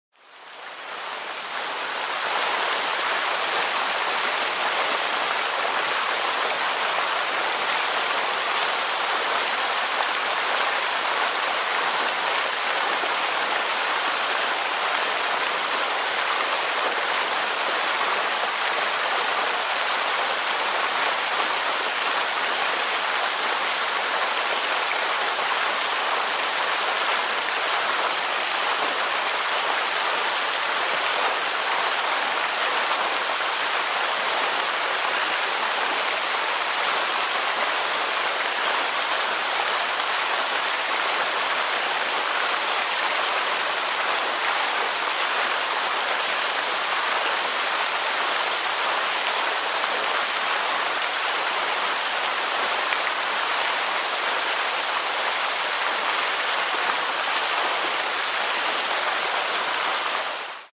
Звуки фонтана
Звук маленького фонтана